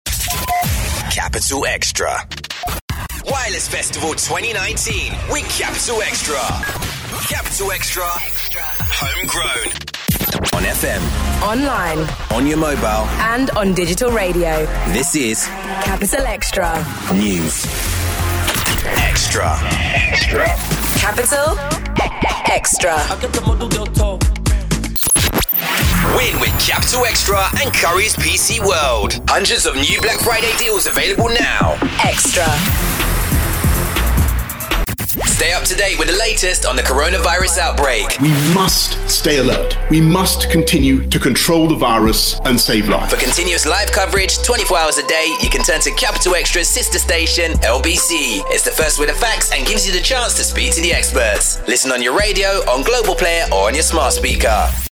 Fresh, commercial and clubby reads
a great young streetwise tone, and a versatile range. Excellent in promos and commercials.